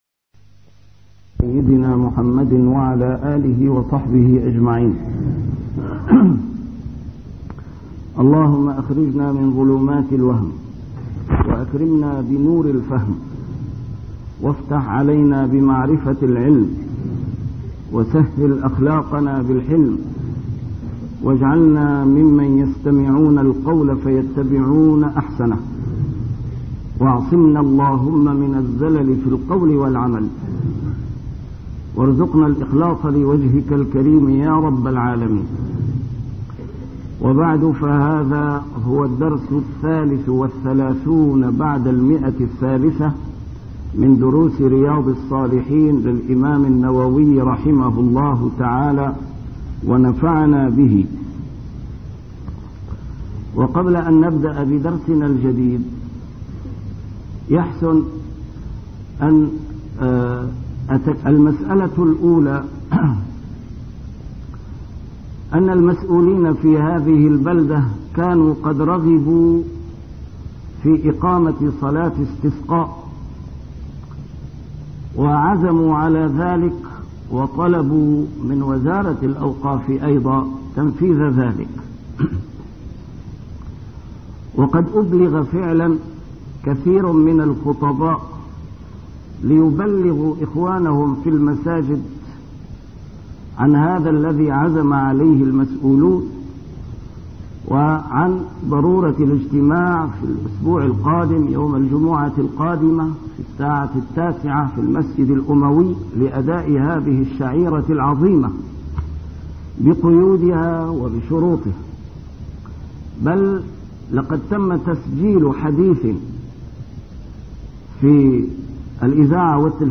A MARTYR SCHOLAR: IMAM MUHAMMAD SAEED RAMADAN AL-BOUTI - الدروس العلمية - شرح كتاب رياض الصالحين - 332- الاستسقاء وحرب الخليج